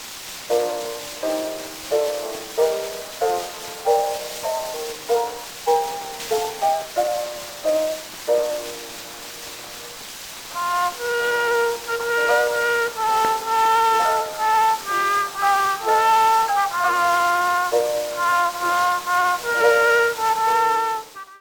CORNET
original double-sided recordings made 1900-1922